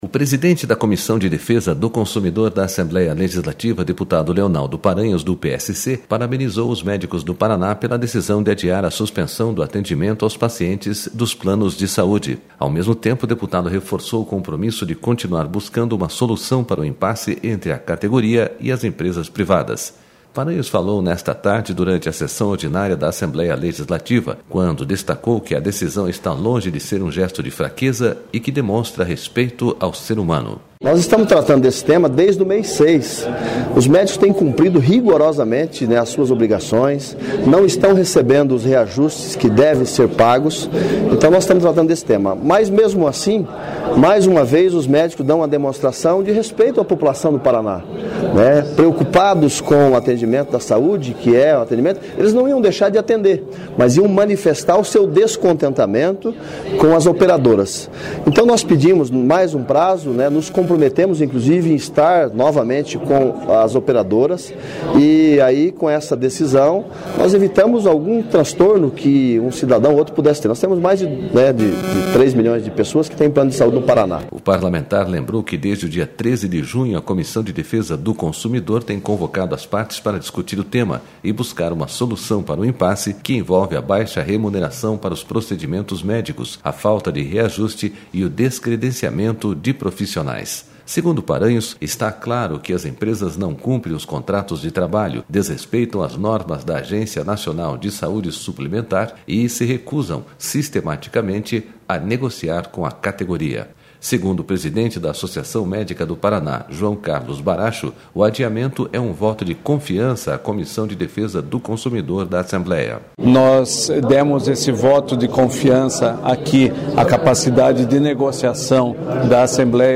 Paranhos falou nesta tarde, durante a Sessão Ordinária da Assembleia Legislativa, quando destacou que a decisão está longe de ser um gesto de fraqueza e demonstra respeito ao ser humano.//